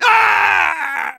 Voice file from Team Fortress 2 Russian version.
Soldier_painsevere05_ru.wav